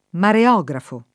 [ mare 0g rafo ]